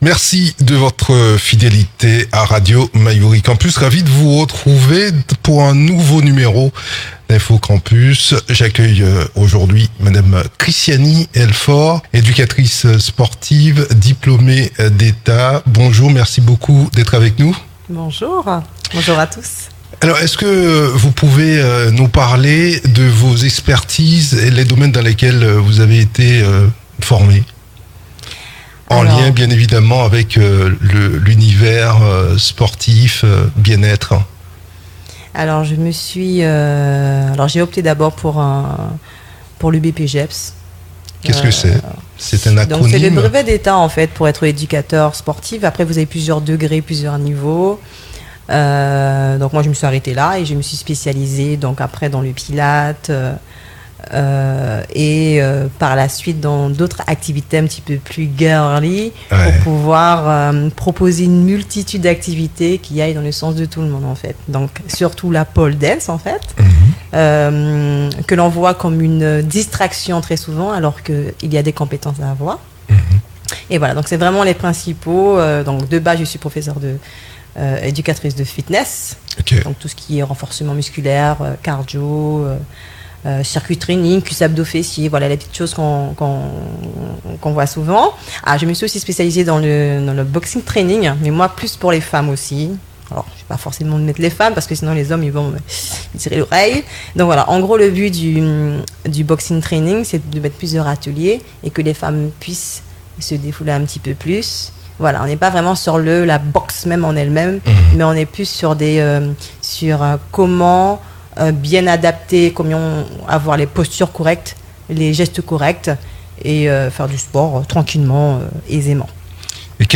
Elle était notre invitée dans info Campus à Radio Mayouri Campus.